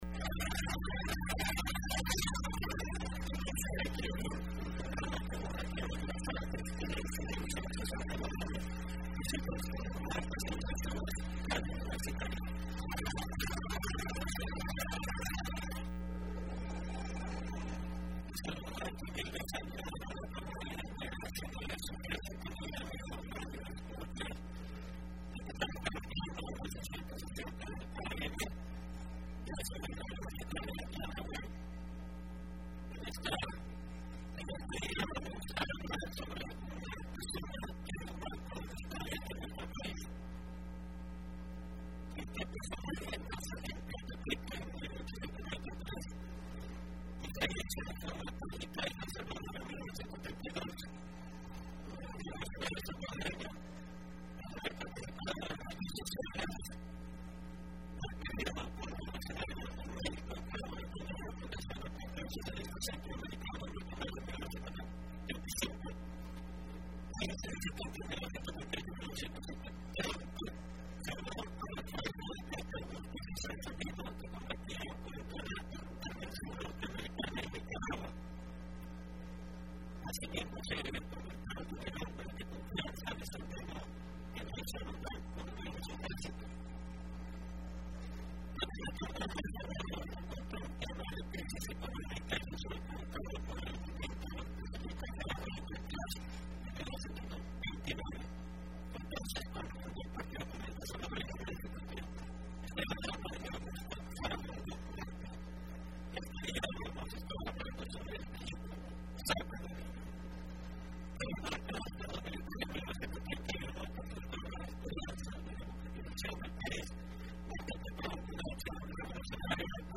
Entrevista programa Aequilibrium (19 mayo 2015): Iconografía de Agustín Farabundo Martí , personaje de la revolución salvadoreña